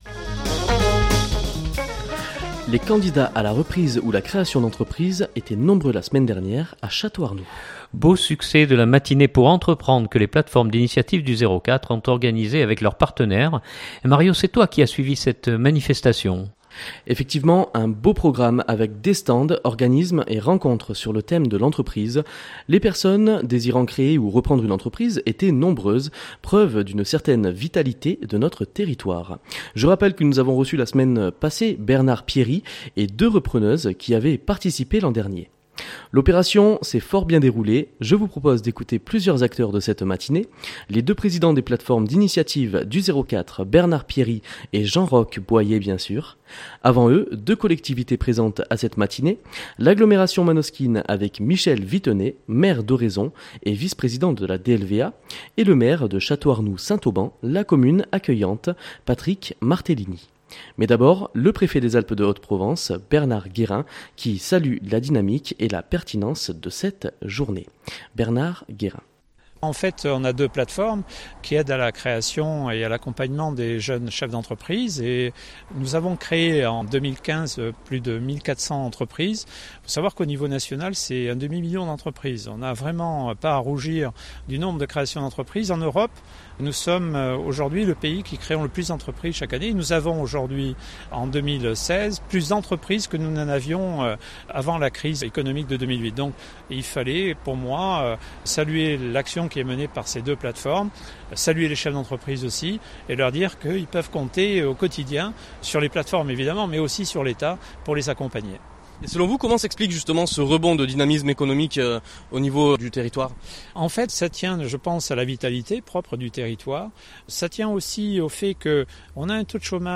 Avant eux deux collectivités présentes à cette matinée, l’Agglomération manosquine avec Michel Vittenet, maire d’Oraison et vice-président de la DLVA et le maire de Château-Arnoux-Saint-Auban, la commune accueillante, Patrick Martellini.